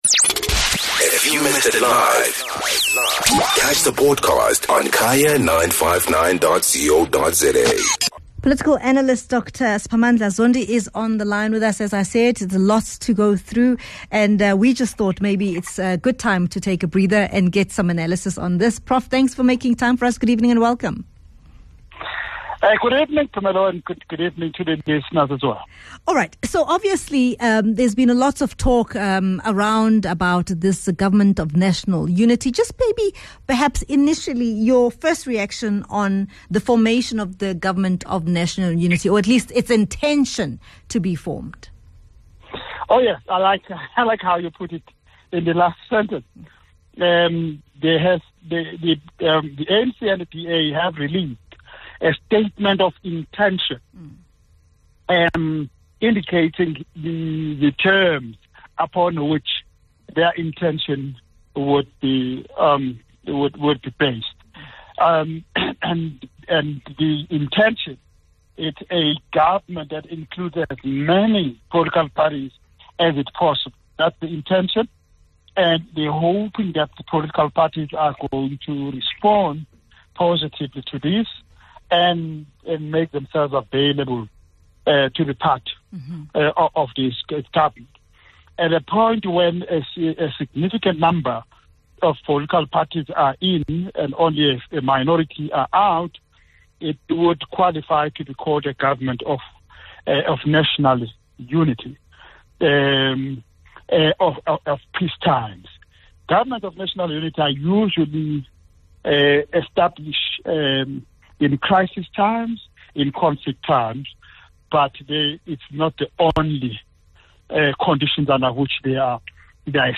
Political Analyst